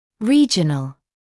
[‘riːʤənl][‘риːджэнл]региональный